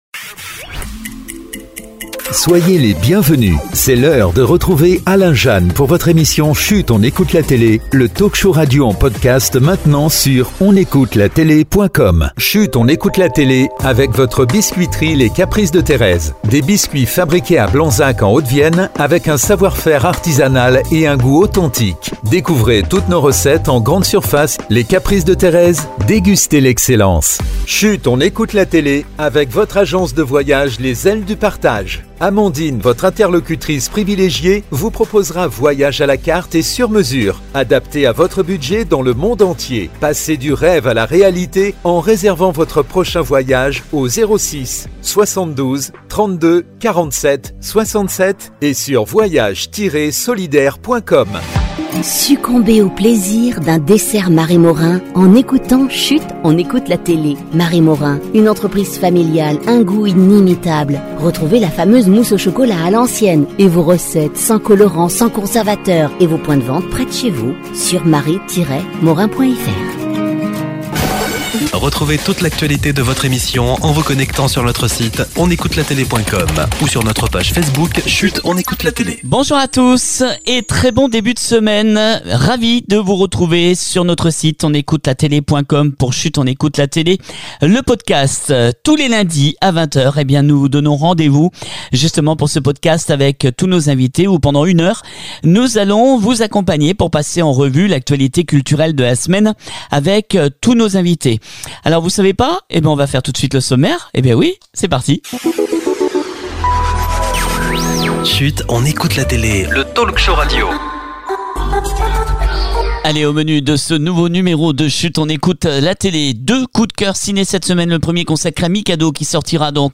On se retrouve ce lundi 7 Avril 2025 pour un nouveau rendez vous de Chut on écoute la télé avec de nombreux invités, on parle de